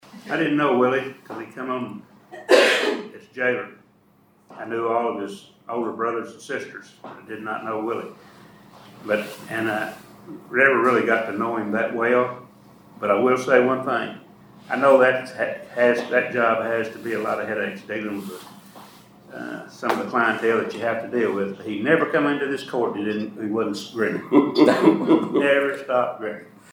The Caldwell County Fiscal Court paused its Tuesday session to honor the late Jailer Willie Harper, who was remembered fondly for his dedication, financial stewardship, and ever-present smile following his courageous battle with cancer.
District 3 Magistrate Brent Stallins shared that Harper always had a smile on his face.